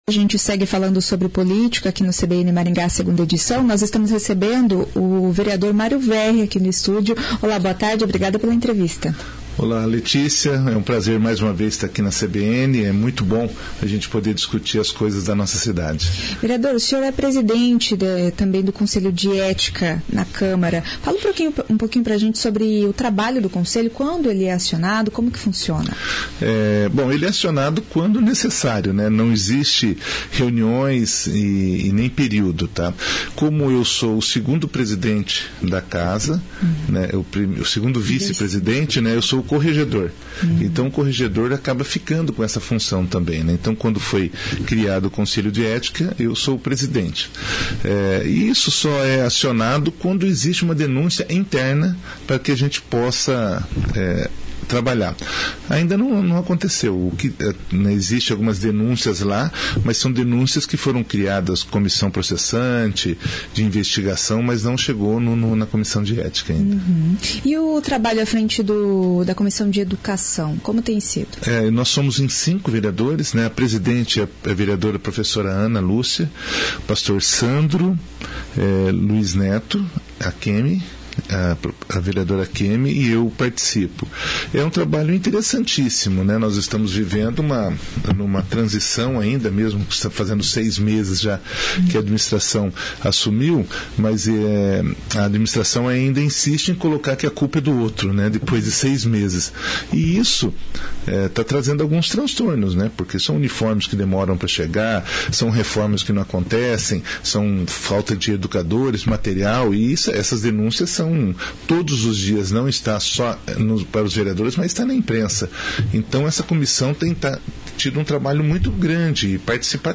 De acordo com o presidente do conselho, vereador Mário Verri (PT), algumas denúncias foram feitas neste ano, mas não chegaram ao Conselho de Ética. Ainda de acordo com Verri, este primeiro ano de legislatura tem sido difícil por causa do maior número de vereadores e a quantidade de projetos protocolados, mas que acabam sendo inconstitucionais. Ouça a entrevista.